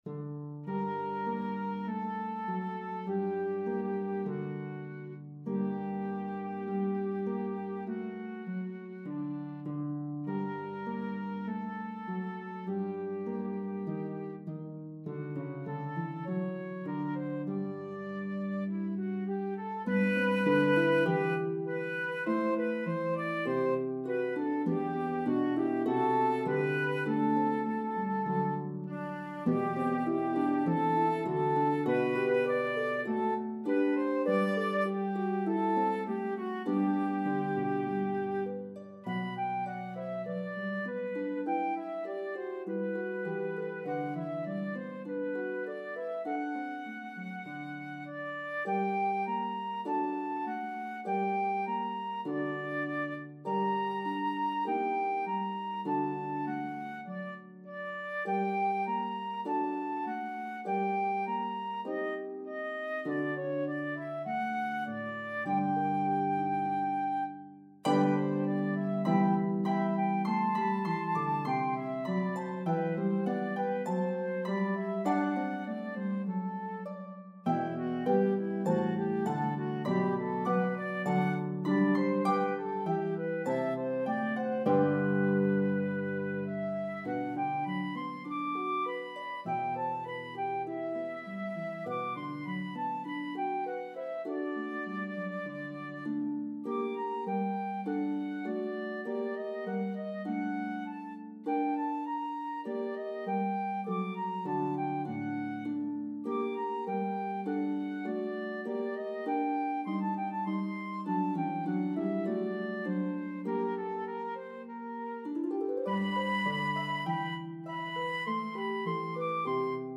This cherished Carol recounts the birth of Christ.